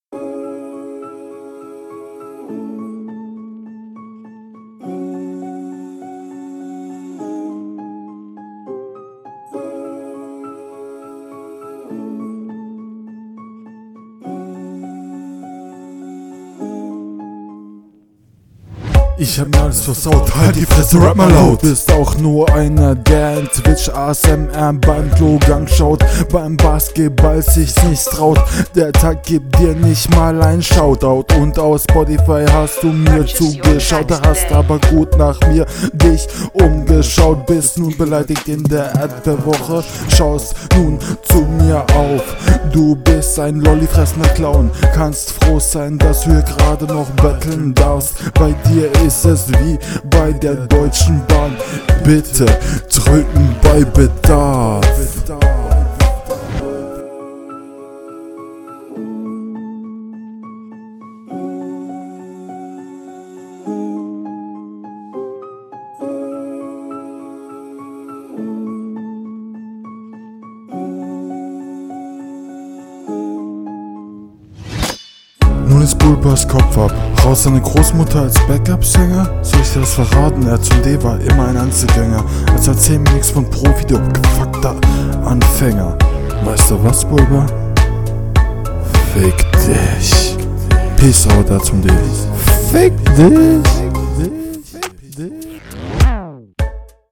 Hier unterlegen, Stimme viel zu angestrengt.